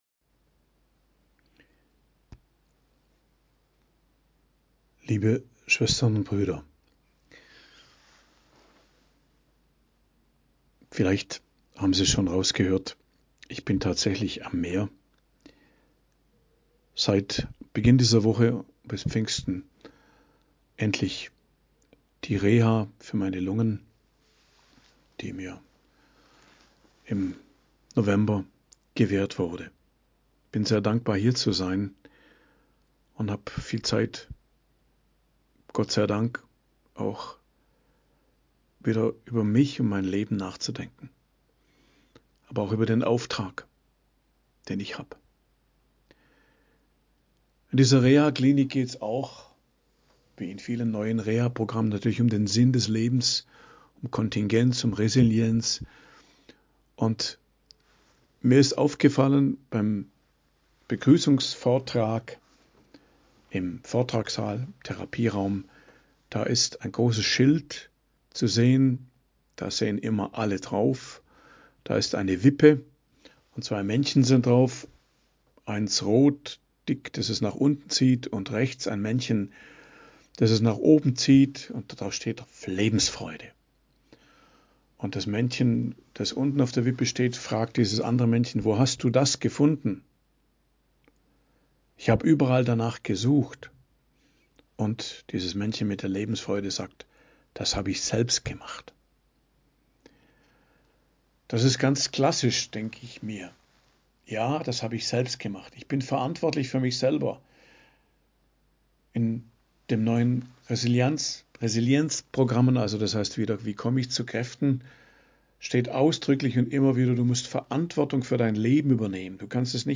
Predigt am Donnerstag der 5. Osterwoche, 2.05.2024 ~ Geistliches Zentrum Kloster Heiligkreuztal Podcast